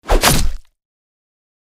دانلود آهنگ دعوا 7 از افکت صوتی انسان و موجودات زنده
دانلود صدای دعوای 7 از ساعد نیوز با لینک مستقیم و کیفیت بالا
جلوه های صوتی